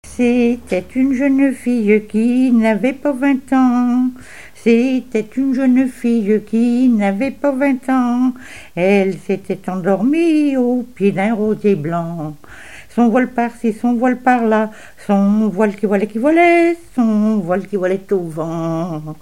gestuel : à marcher
Genre laisse
Pièce musicale inédite